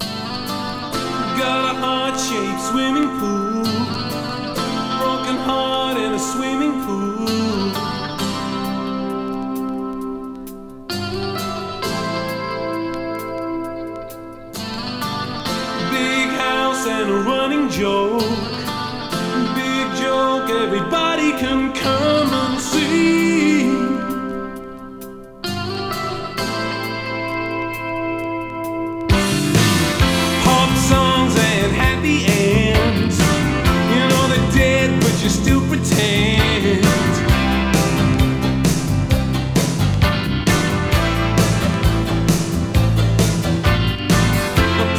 前作での路線を突き抜け、フレッシュで良質なポップスが鳴らされた傑作。魅力的なメロディが溢れ、アレンジも凝ってます。”
Rock, Pop, Indie　UK　12inchレコード　33rpm　Stereo